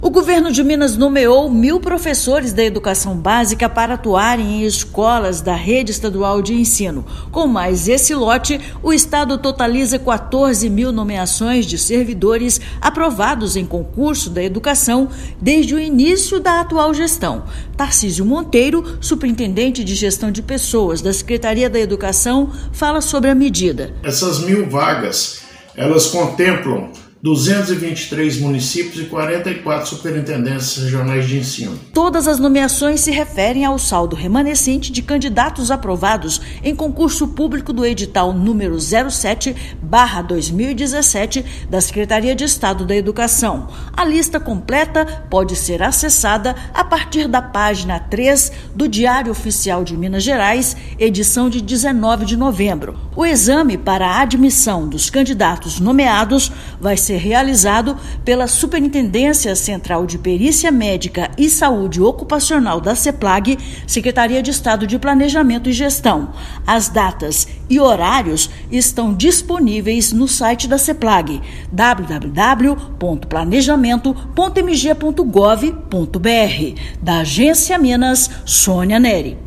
O Governo de Minas nomeou mais mil professores da Educação Básica para atuarem em escolas da rede estadual de ensino. Ouça matéria de rádio.